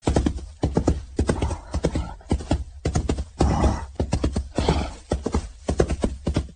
rhinoceros.mp3